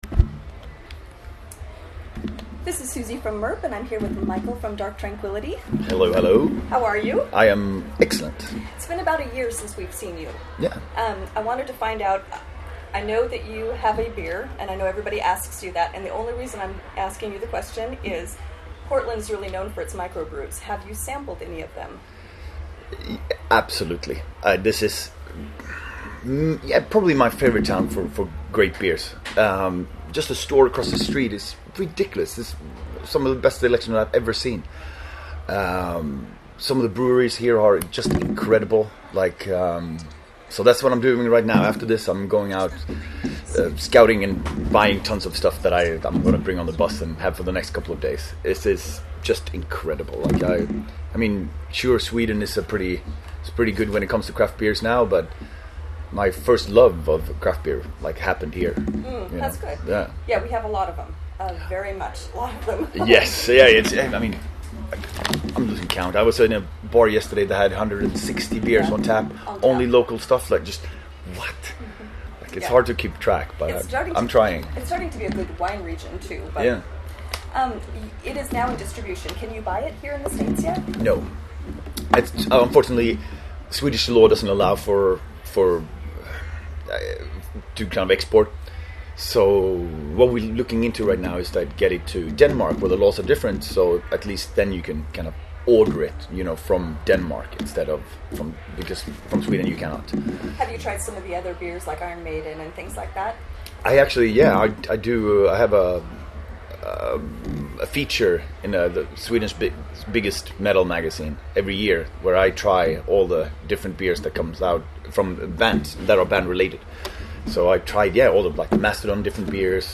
Interview with Mikael Stanne of Dark Tranquiility - Portland, Oregon - 2017 | MIRP
Venue: Hawthorne Theater – Portland, Oregon